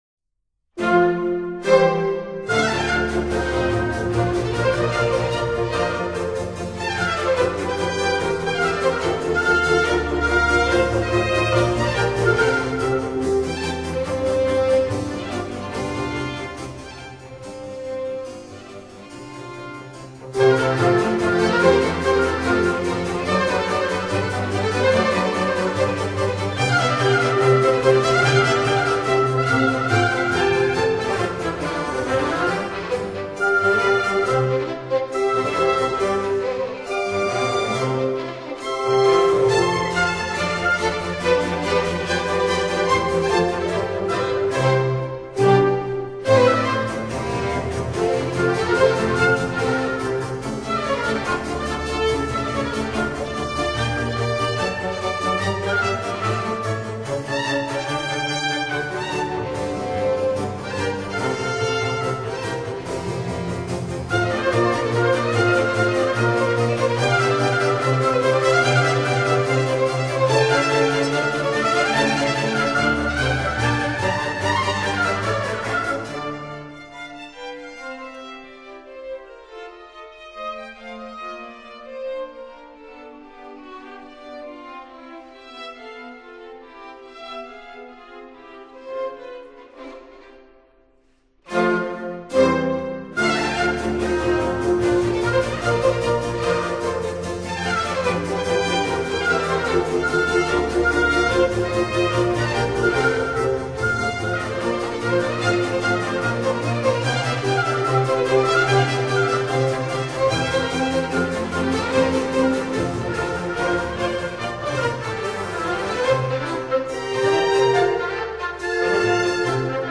【古典巴洛克】